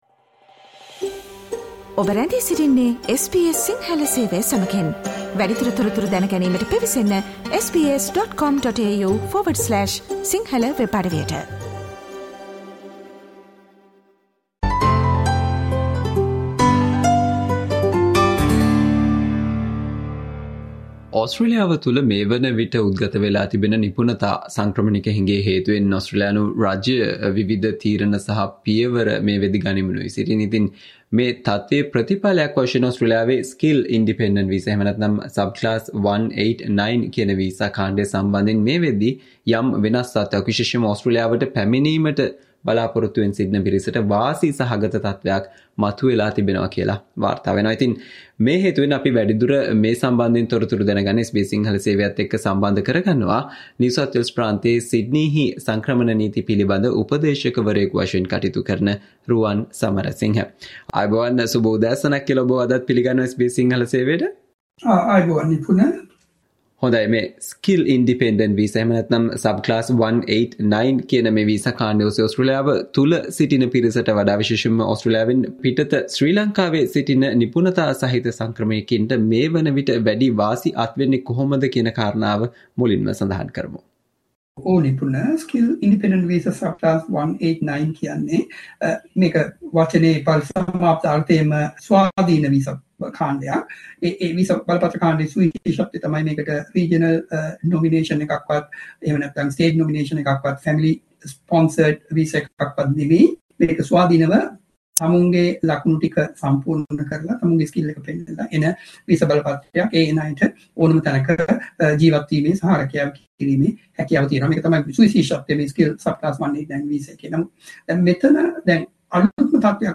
SBS Sinhala discussion on What you need to know about the Changes to Australia's "189 visa" targeting overseas skilled migrants due to Australia's growing skill shortage